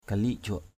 /ka-li:ʔ – ʥʊaʔ/ (d.) dép = sandales. sandals.